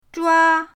zhua1.mp3